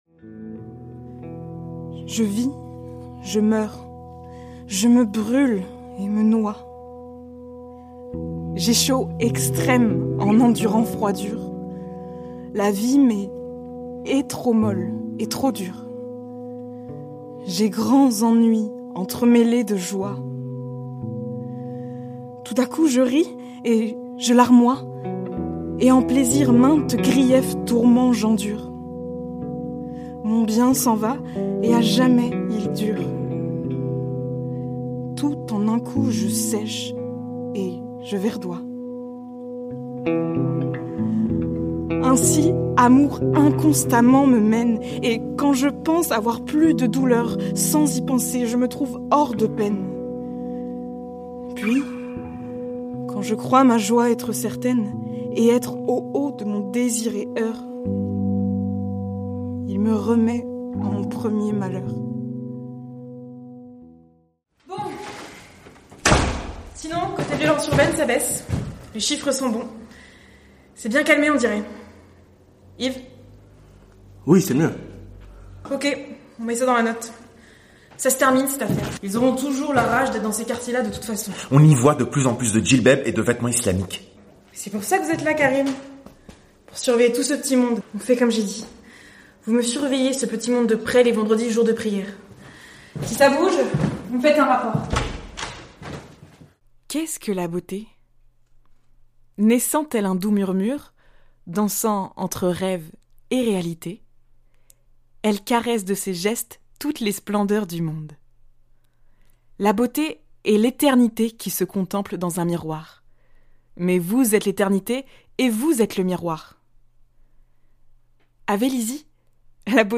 Bandes-son
Narratrice
Voix off